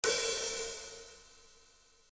今回は、あらかじめ用意した HiHat 音を使います。
Open あるいは、ハーフOpenぎみの音です。
Hat_01.mp3